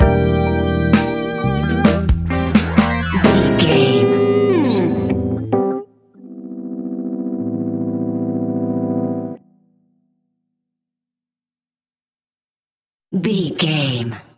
Ionian/Major
E♭
hip hop